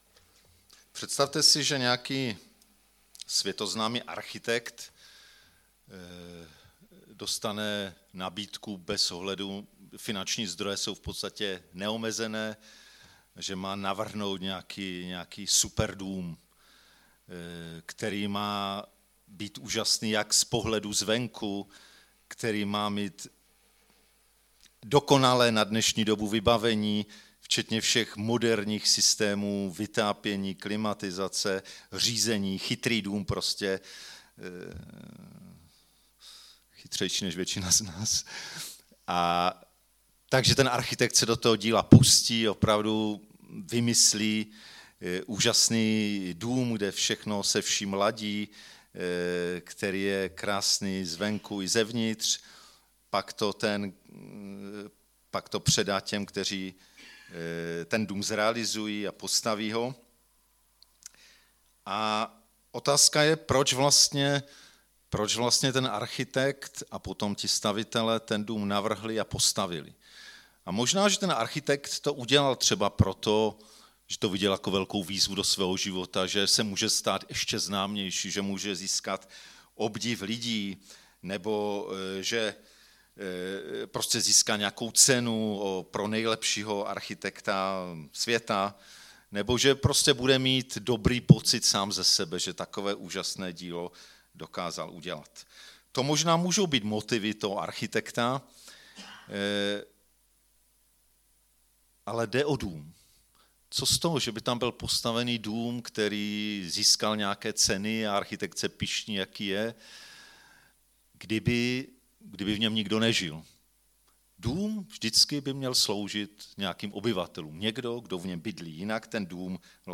2. díl ze série kázání "Ve světle počátku", Gn 1,3-25
Kategorie: nedělní bohoslužby